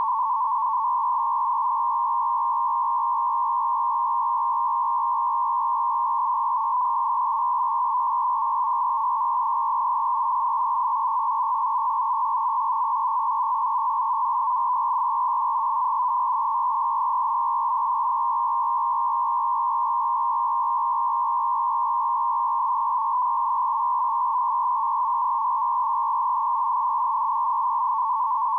PSK220F system audio samples
PSK220F (SERIAL) (220.5 Bd FEC - AMATEUR RADIO MODE) All material Copyright © 1998 - 2008 No content on this website may be used or published without written permission of the author!